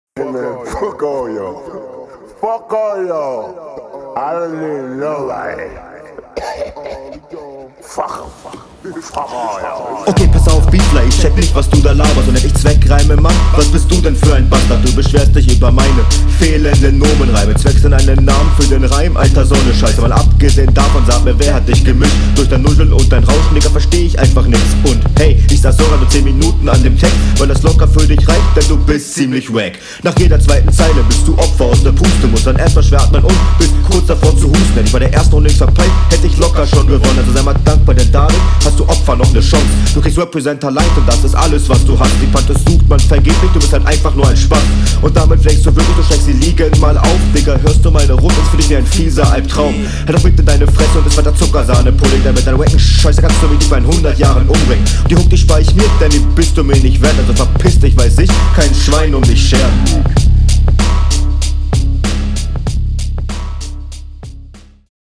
Zur Soundquali: Besser als beim Gegner, man merkt dass du schon …